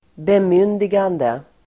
Uttal: [²bemyn:digande]